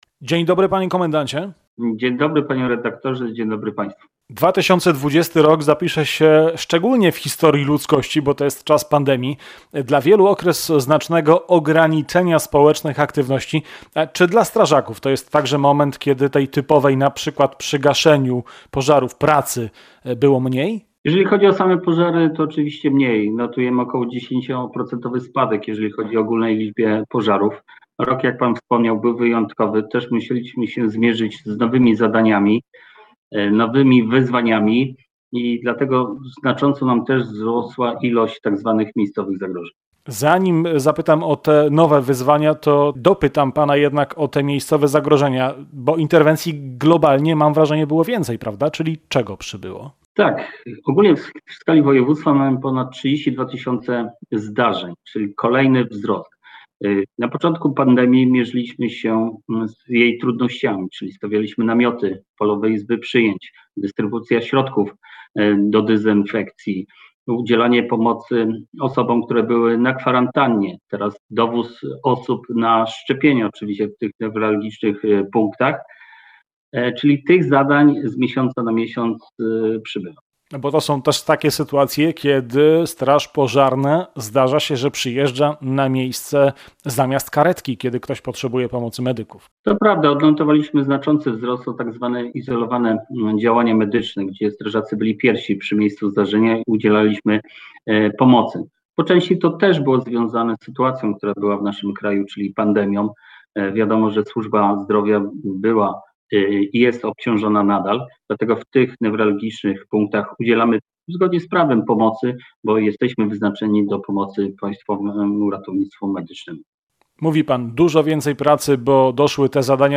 Pomorska Straż Pożarna podsumowała poprzedni rok swojej działalności. Gościem Radia Gdańsk był starszy brygadier Piotr Socha, Komendant Wojewódzki Państwowej Straży Pożarnej w Gdańsku.